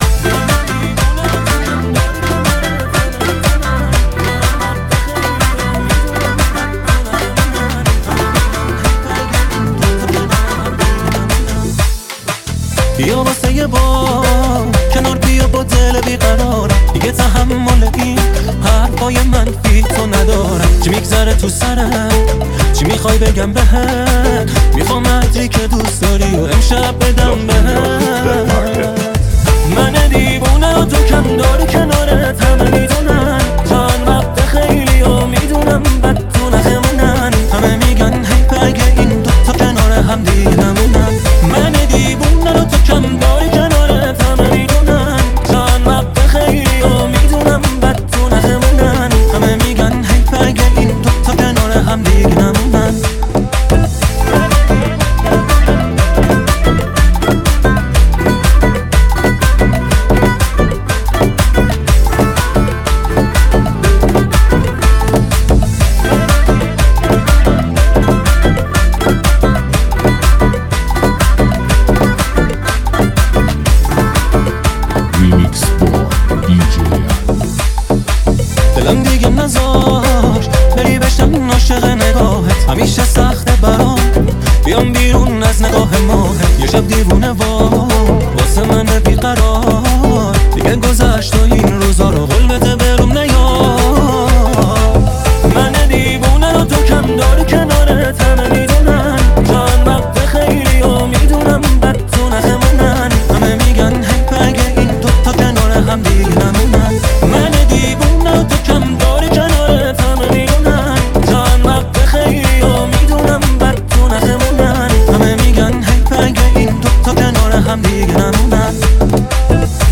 لذت بردن از موسیقی پرانرژی و بیس قوی، هم‌اکنون در سایت ما.